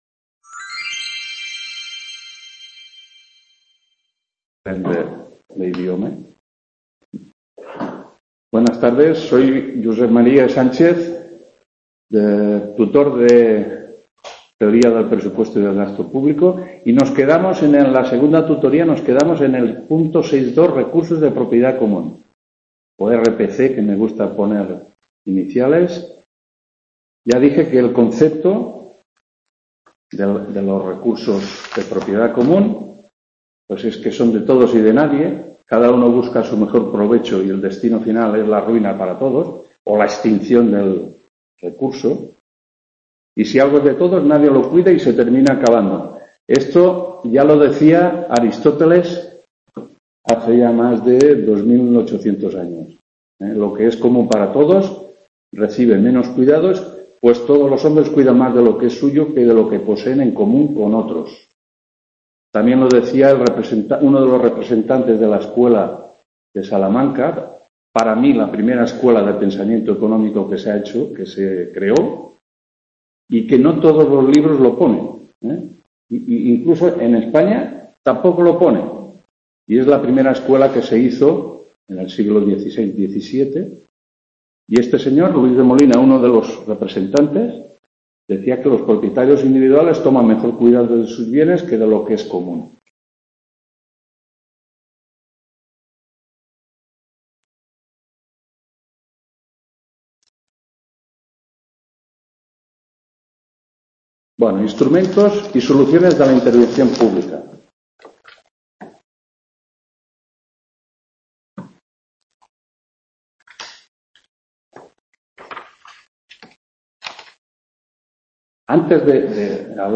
3ª TUTORÍA AVIP 25-11-14 TEORÍA DEL PRESUPUESTO Y DEL GASTO PÚBLICO